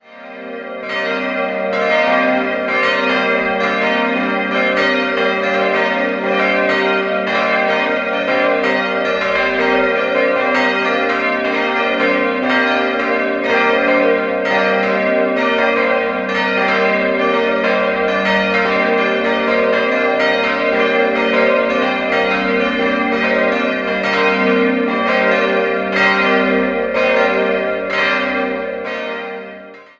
Seit 1973 gab es hier eine Behelfskirche, welche 1983 durch das heutige Gotteshaus samt Gemeindezentrum abgelöst wurde. 4-stimmiges Geläut: g'-b'-c''-d'' Die Glocken wurden 1983 in Karlsruhe gegossen.